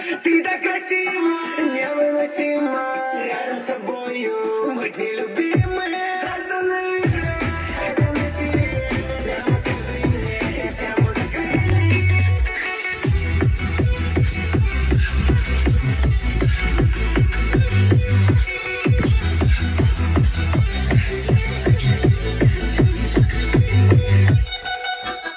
• Качество: 128 kbps, Stereo